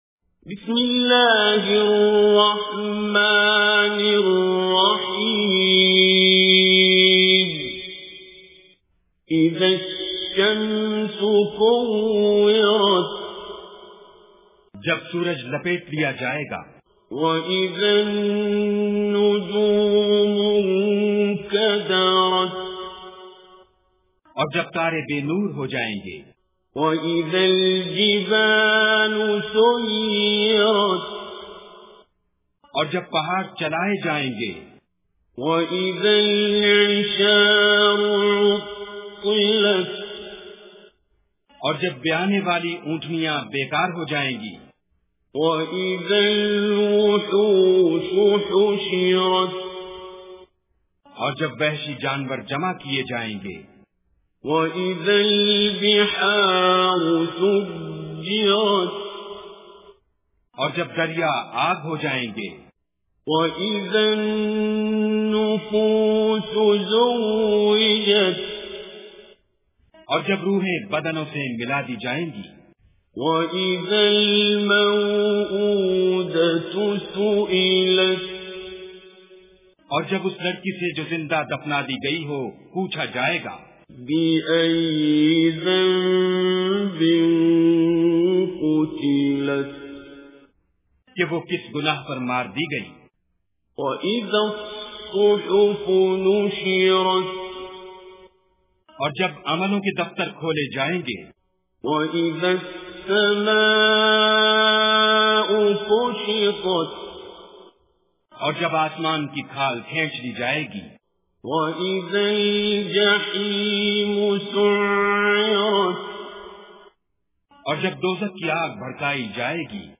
Surah Takwir Recitation with Urdu Translation
Surah Takwir is 81 chapter of Holy Quran. Listen online mp3 recitation of Surah Takwir in Arabic.